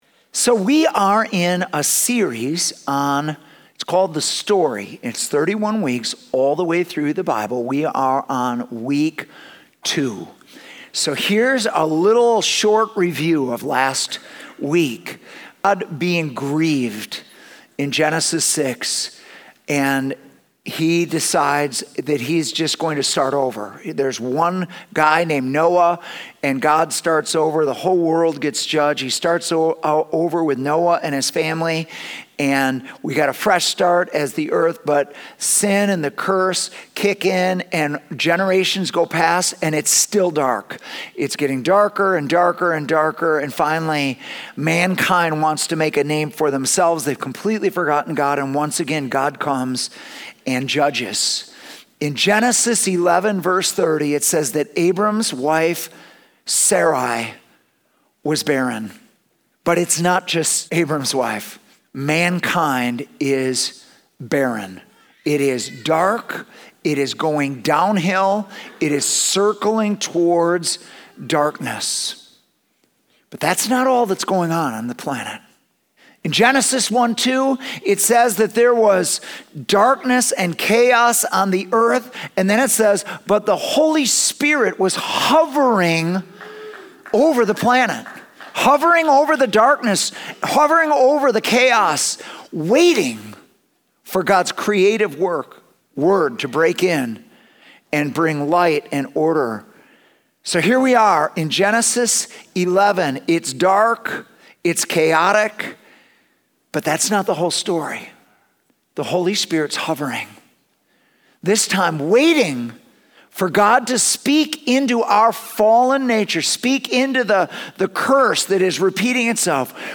Sunday Messages @ City Church